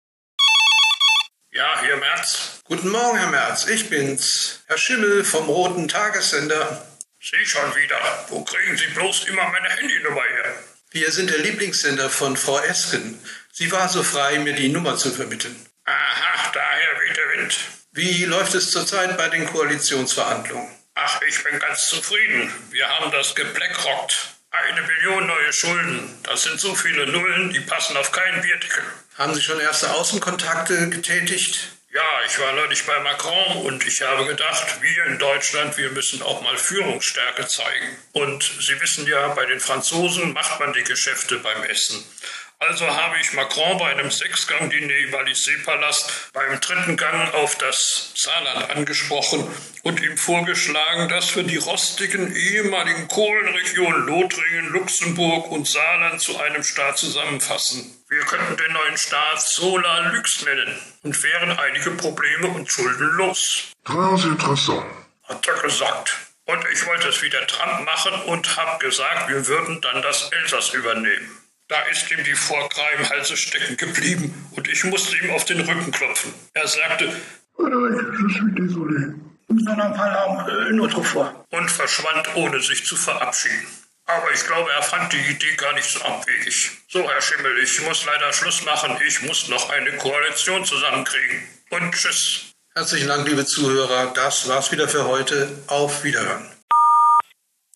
Merz Interview